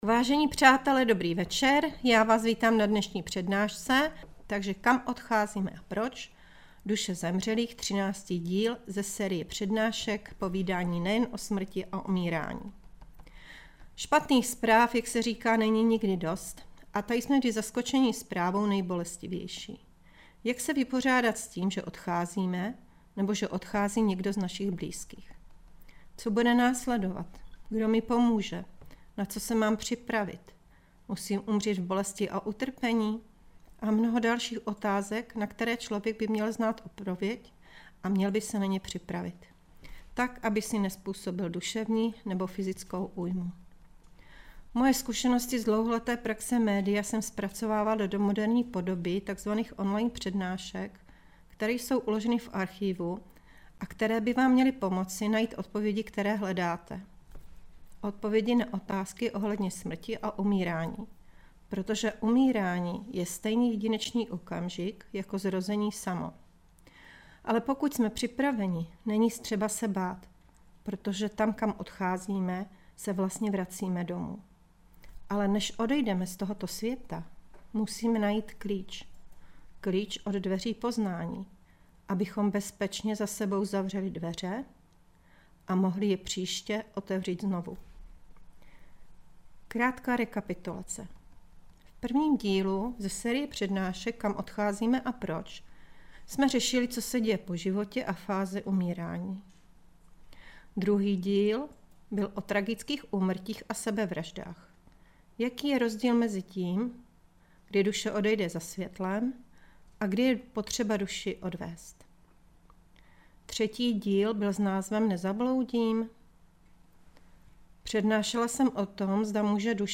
Přednáška Duše zemřelých, díl 13. - Vyvolávání duchů, hrady a jejich energie